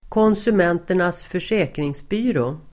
Uttal: [kånsum'en:ter_nas ²för_s'ä:kringsby:rå]